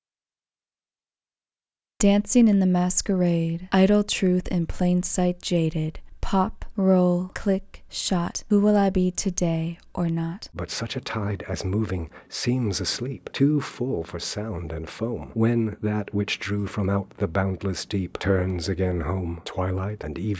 ref_woman_man_16k.wav